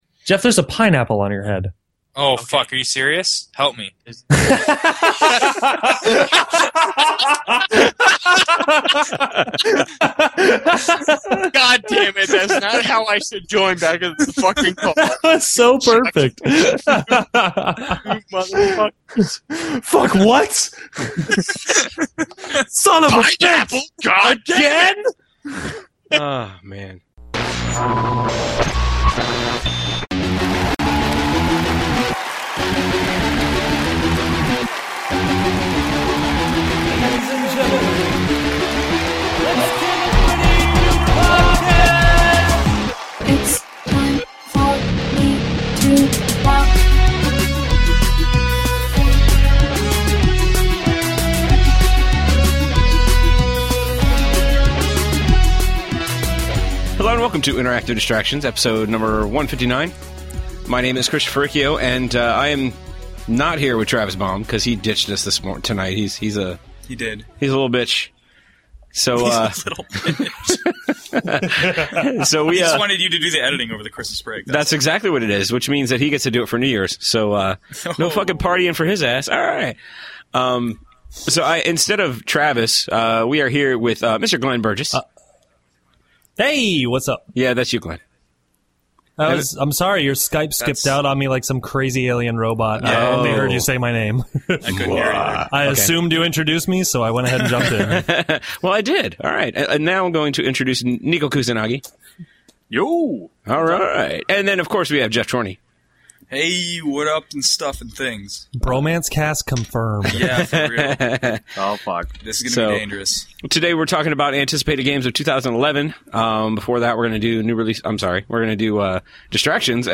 To make matters worse, someone’s connection was producing noise on the back up track. I cleaned it up as much as I could, hopefully you guys don’t notice or it’s not bad enough where you can’t enjoy the second half of the show. After the break we come back and talk about big games hitting in 2011.